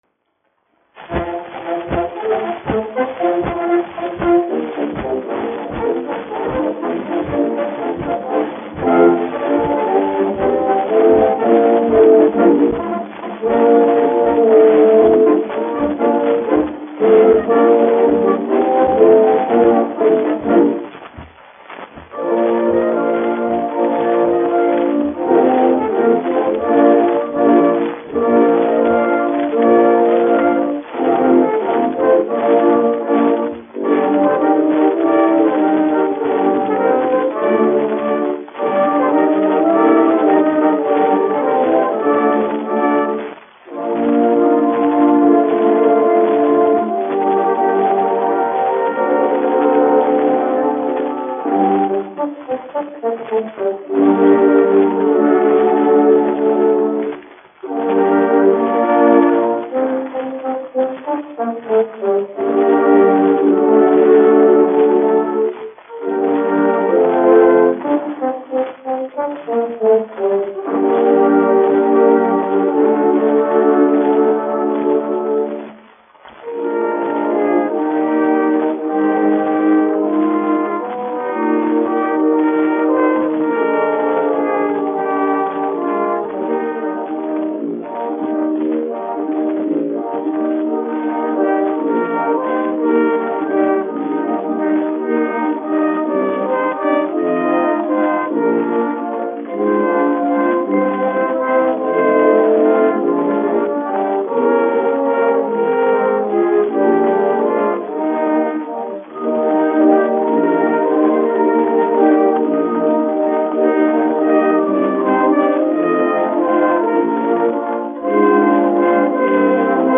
1 skpl. : analogs, 78 apgr/min, mono ; 25 cm
Tautas mūzika -- Latvija
Pūtēju orķestra mūzika
Skaņuplate
Latvijas vēsturiskie šellaka skaņuplašu ieraksti (Kolekcija)